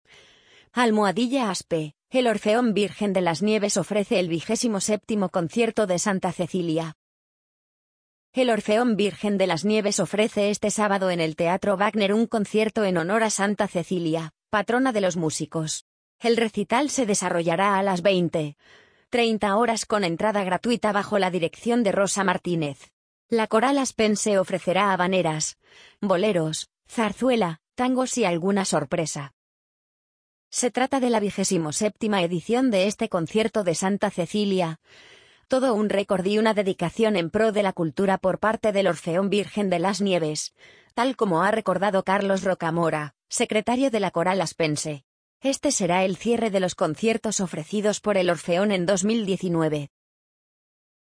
amazon_polly_38226.mp3